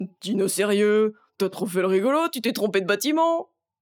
VO_LVL1_EVENT_Mauvais batiment_04.ogg